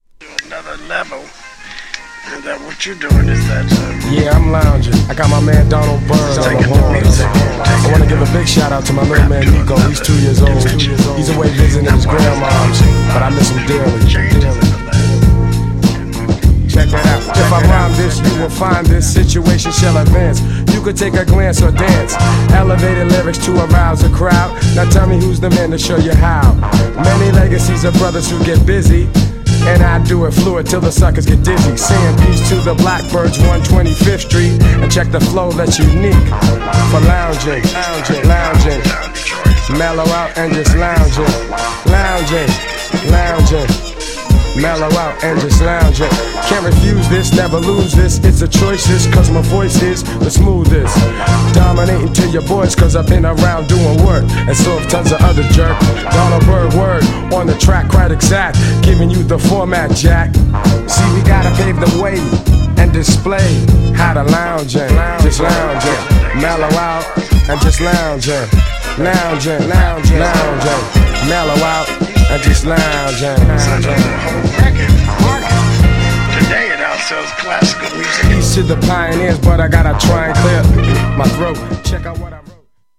GENRE Hip Hop
BPM 96〜100BPM
featに女性ボーカル
JAZZY
R&Bテイスト
SMOOTH_HIPHOP # メロディアスなHIPHOP